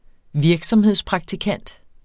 Udtale [ ˈviɐ̯gsʌmheðs- ]